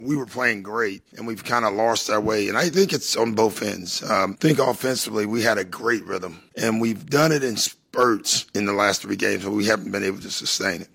Rivers also talked about the Bucks last 3 losses.